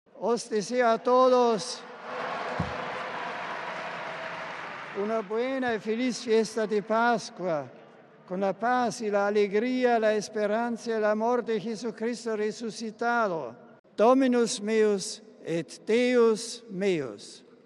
Y tras este Mensaje, el Santo Padre ha felicitado las pascuas en 62 lenguas.